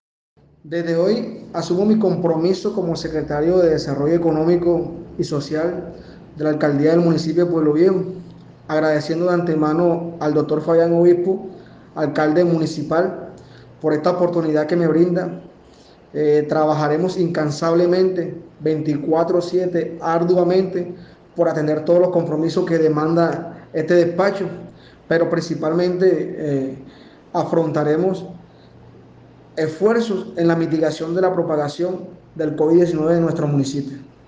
DECLARACIAONES NUEVO SECRETARIO DE DESARROLLO SOSTENIBLE FUENTE: OFICINA DE PRENSA Y COMUNICACIONES ALCALDÍA MUNICIPAL DE PUEBLO VIEJO.